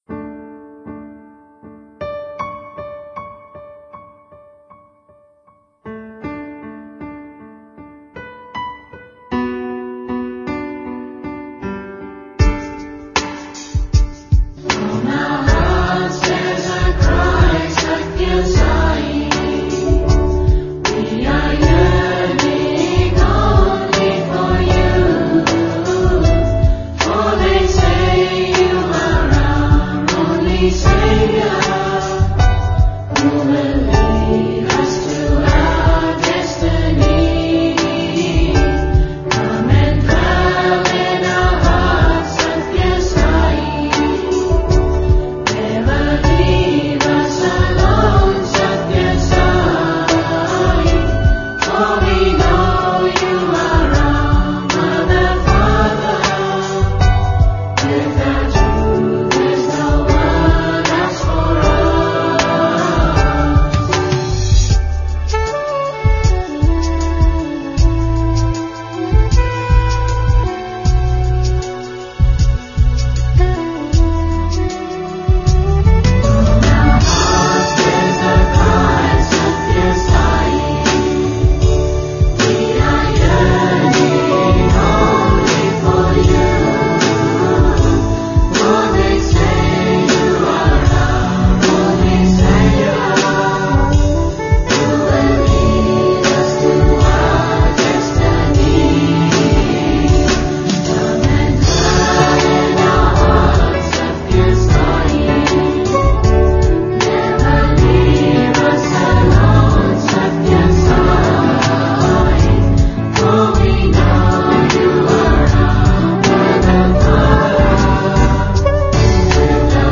1. Devotional Songs
Major (Shankarabharanam / Bilawal)
8 Beat / Keherwa / Adi
Medium Slow
5 Pancham / G
2 Pancham / D
Lowest Note: p / G (lower octave)
Highest Note: P / G